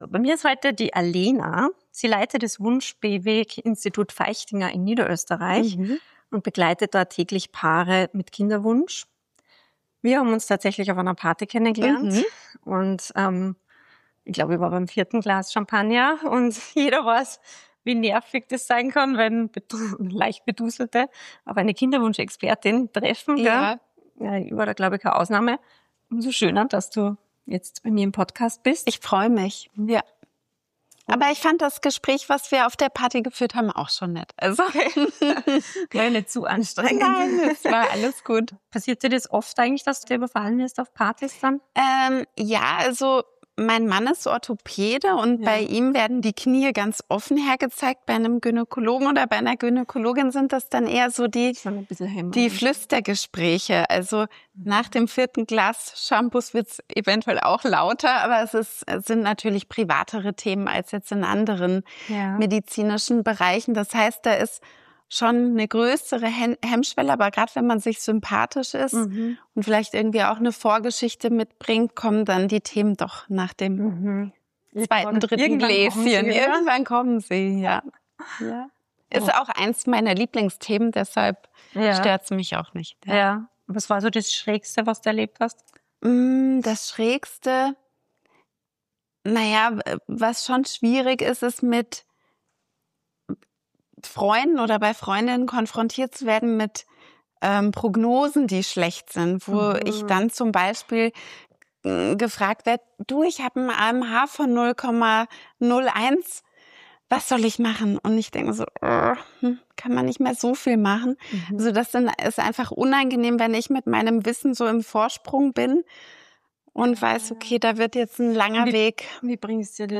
Wir reden über Erwartungen, medizinische Realität und diese Momente, in denen man merkt, dass der eigene Plan nicht ganz aufgeht. Über Genetik, Zahlen, Hoffnung – und die Erkenntnis, dass es manchmal eben nicht nur in unserer Hand liegt. Ein Gespräch, das persönlich ist, überraschend – und ziemlich entwaffnend ehrlich.